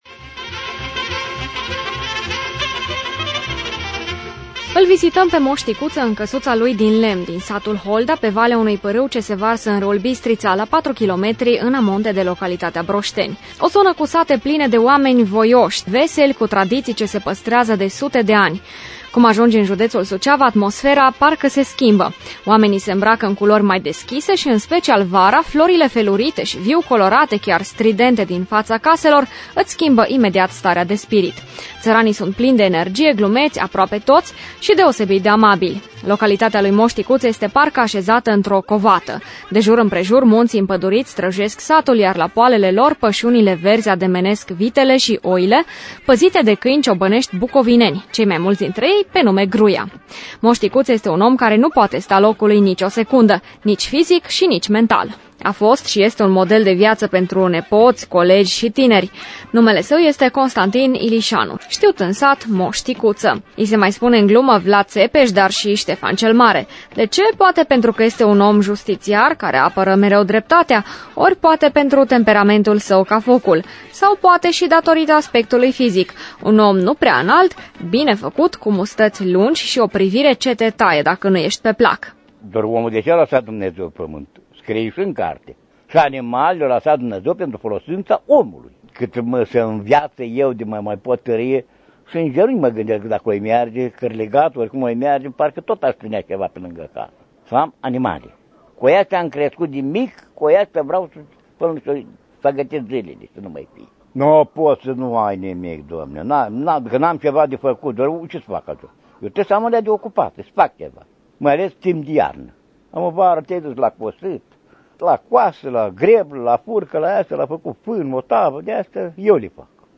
I-a mai ramas acum vocea cu timbru aspru, hotărâtă, care mai arată puterea lui interioară.